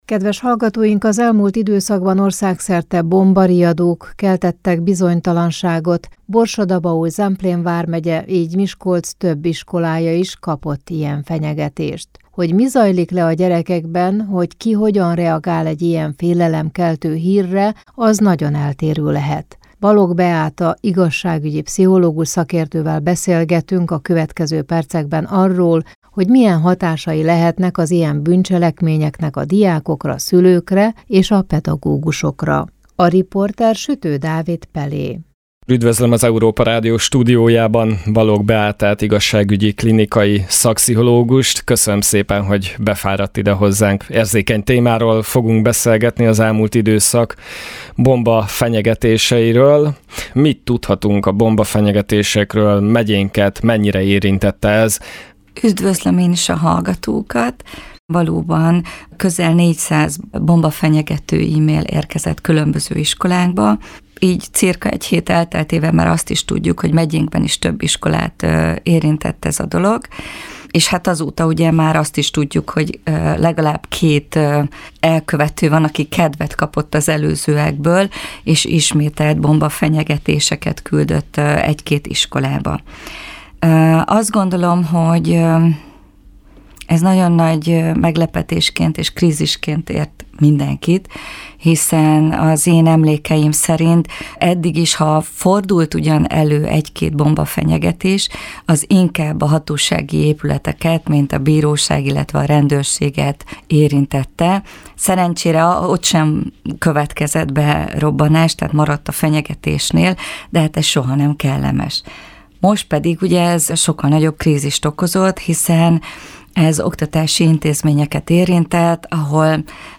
15_15_bombafenyegetesek_pszichologus.mp3